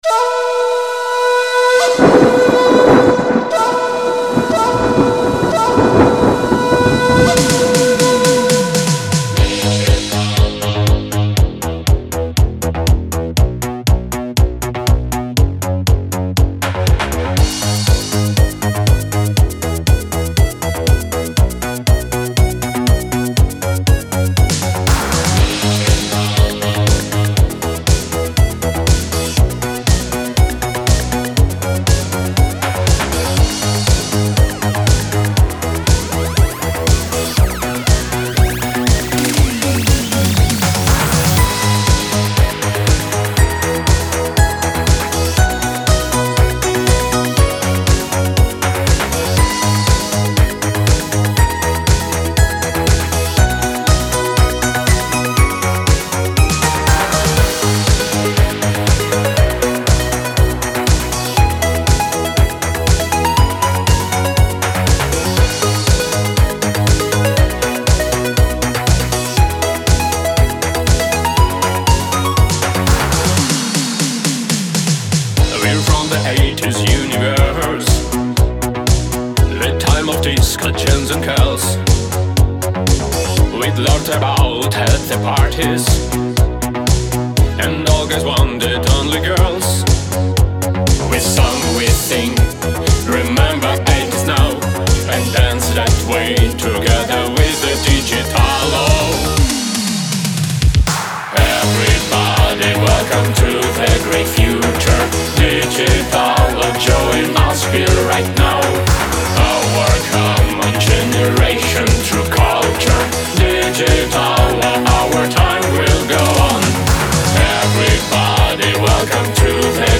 Disco Italo Disco
спасибо, живенько так:)